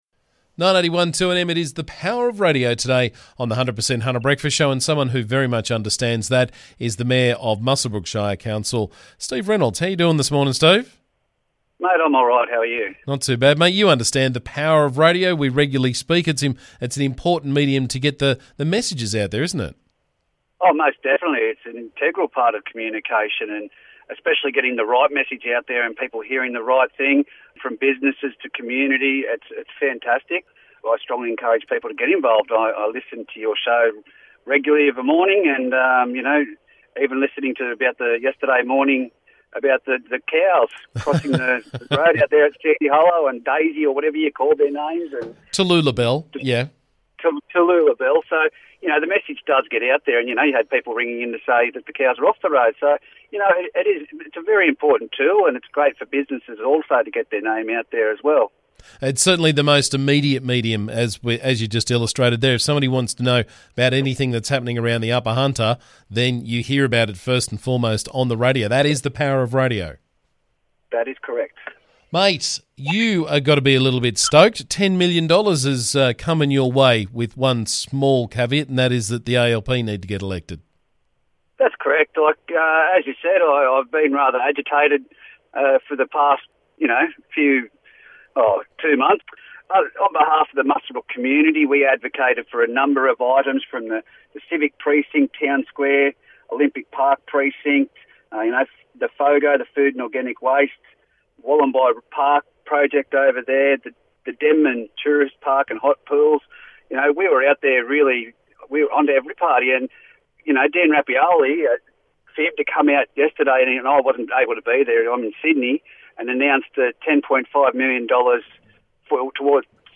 Muswellbrook Shire Council Mayor Steve Reynolds was on the show this morning with the latest from around the district.